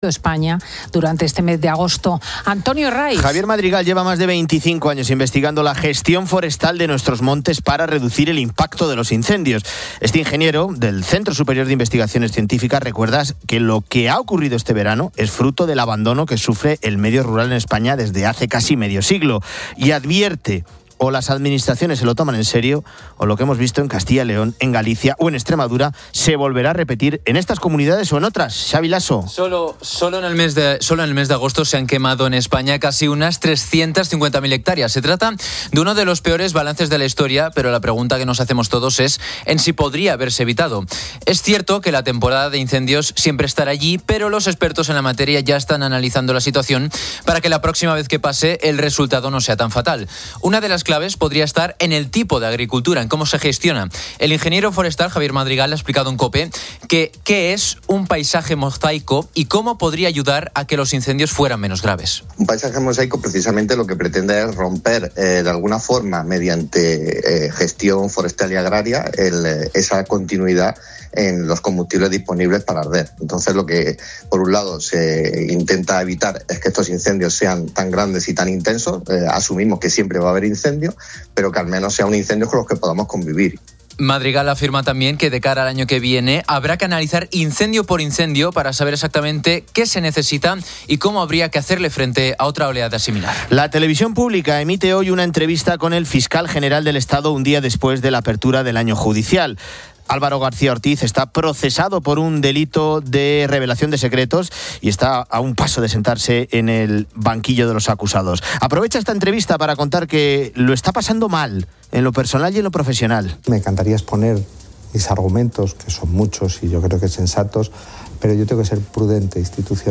El audio abarca varios temas de actualidad y entrevistas.